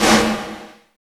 27 SN VERB-R.wav